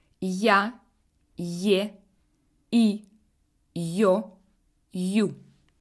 2.Si la consonne est suivie d'une voyelle de deuxième série [4]: (я, е, и, ё, ю), on dit qu'elle est molle ou mouillée.